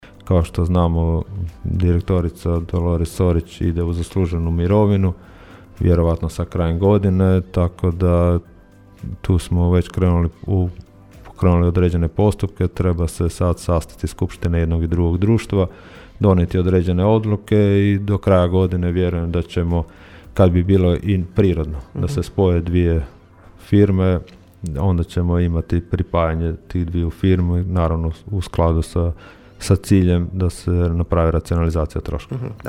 U subotnjim Gradskim minutama gradonačelnik Labina Donald Blašković govorio je o promjenama u funkcioniranju gradskih tvrtki te je najavio spajanje Labin stana i Labina 2000 u jednu tvrtku: (